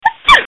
Woman sneeze